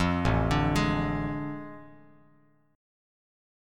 AM7sus4#5 chord